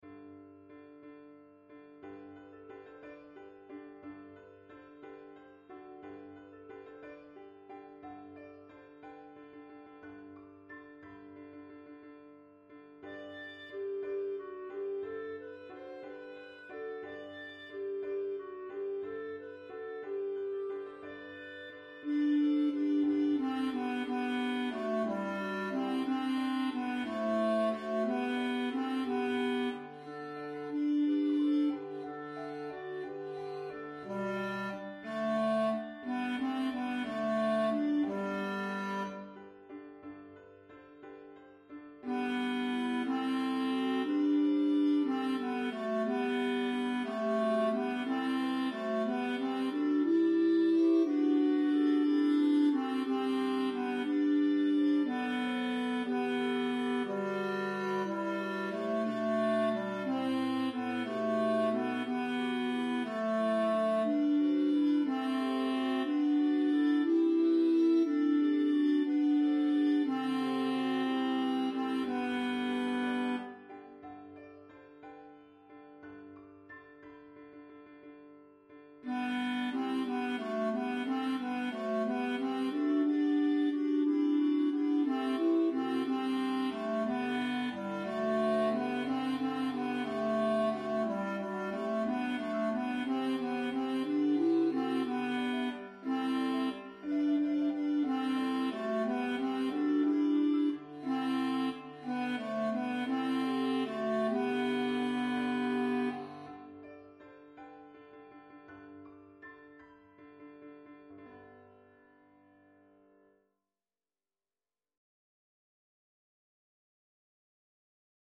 Un Flambeau (arr Rutter)     Your part emphasized:
Tenor    Bass